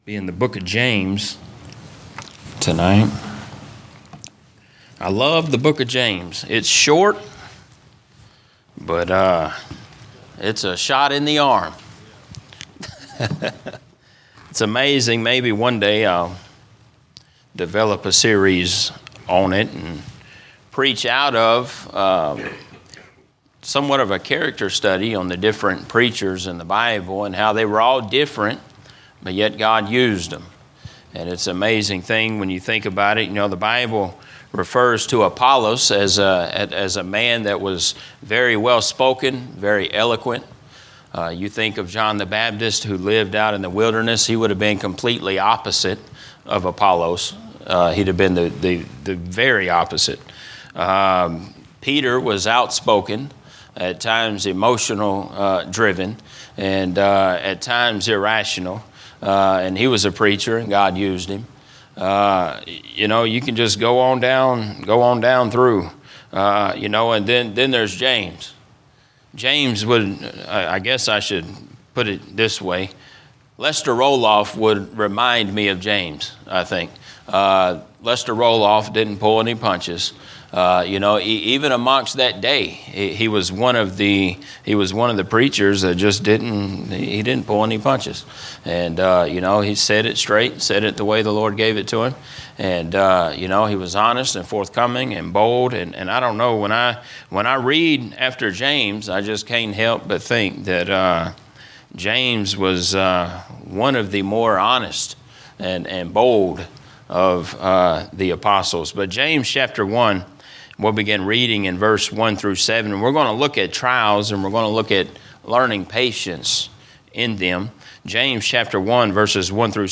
A message from the series "General Preaching."